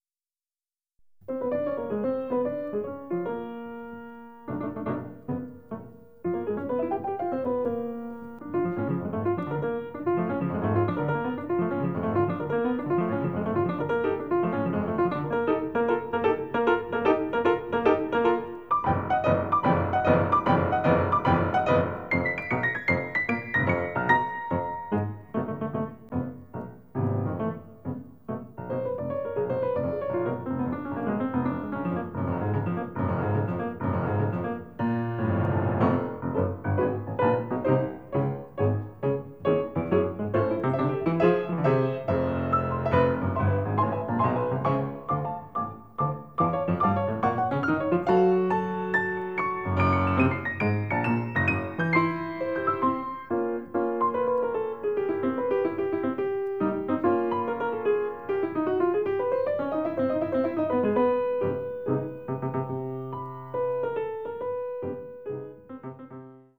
La sonata - Corso di studio svolto al Conservatorio "G.Verdi" di Torino nel maggio del 2000 dedicato alla "sonata pianistica", nell'ambito del potenziamento e della formazione musicale e professionale degli allievi.